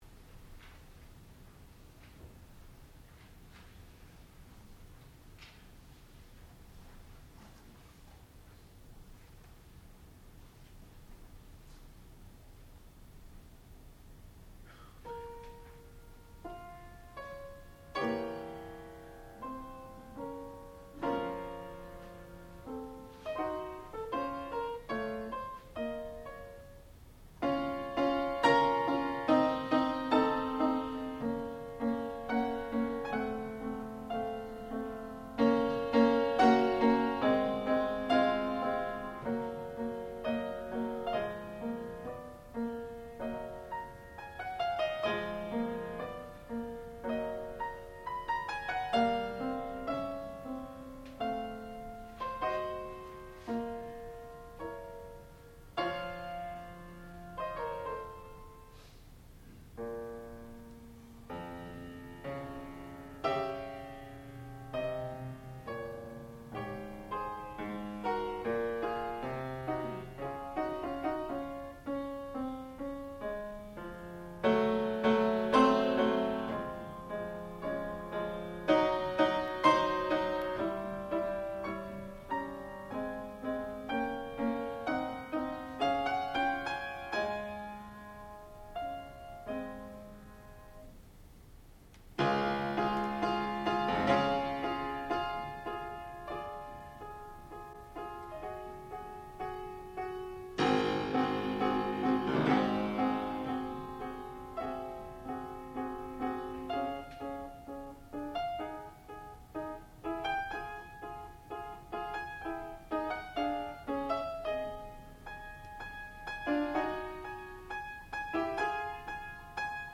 sound recording-musical
classical music
fortepiano